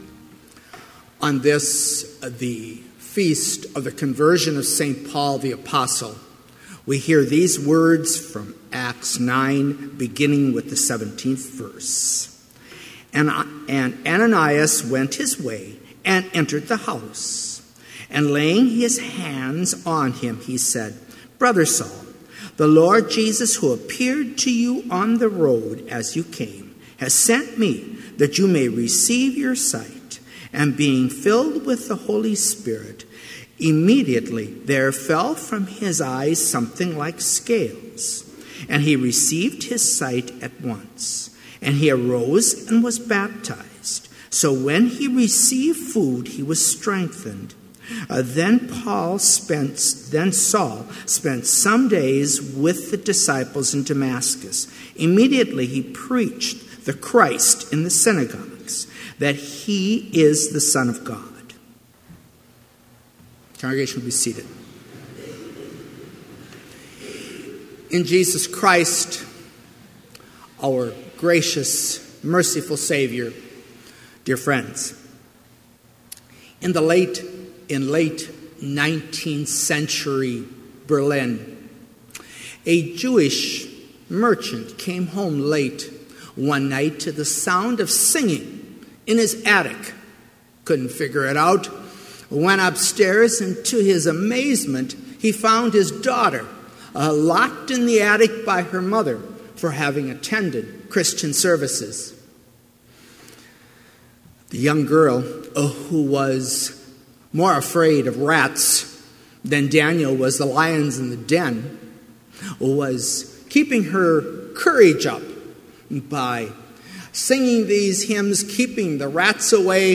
Complete service audio for Chapel - January 25, 2017